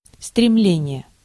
Ääntäminen
IPA : /kɹeɪ.vɪŋ/